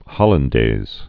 (hŏlən-dāz)